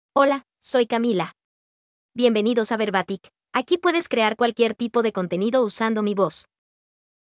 Camila — Female Spanish (Peru) AI Voice | TTS, Voice Cloning & Video | Verbatik AI
FemaleSpanish (Peru)
Camila is a female AI voice for Spanish (Peru).
Voice sample
Camila delivers clear pronunciation with authentic Peru Spanish intonation, making your content sound professionally produced.